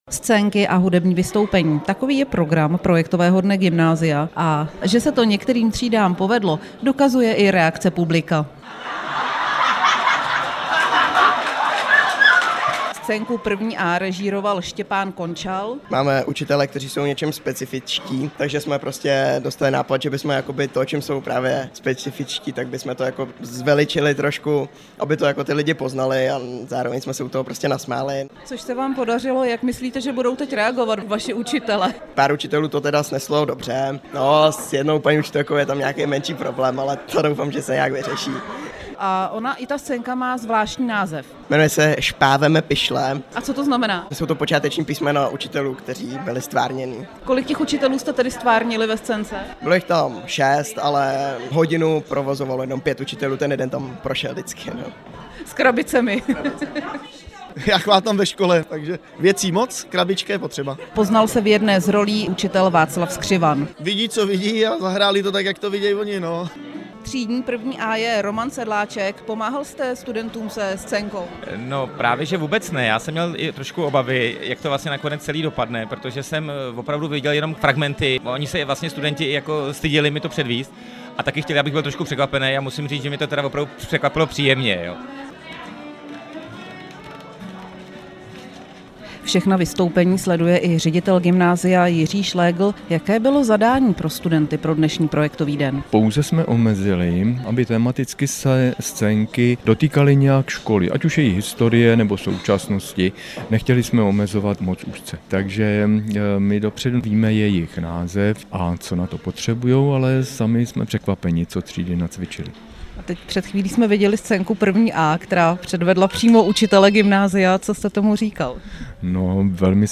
Klatovský kulturní dům dnes dopoledne zaplnili studenti vyšších ročníků gymnázia.